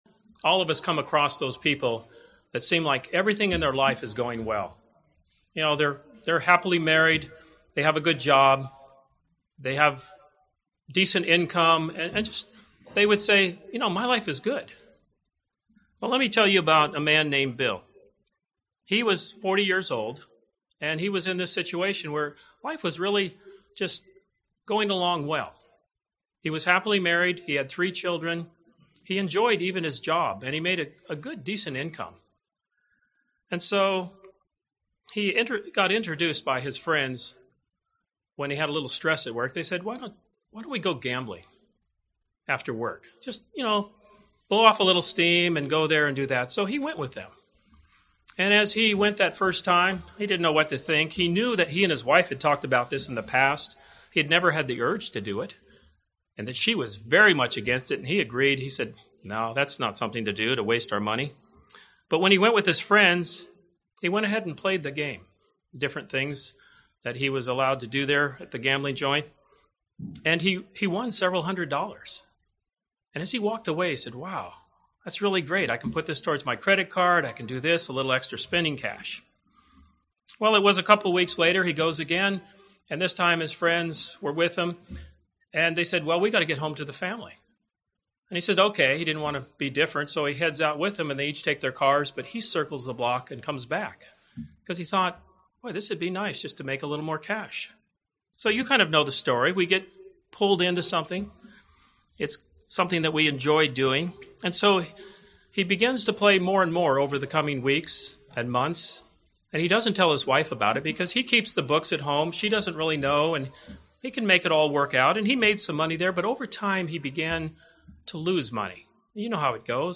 The offer of salvation is a supreme gift, but God does require complete submission. This sermon is part 1 and covers two important steps in surrendering to God.
Given in Orlando, FL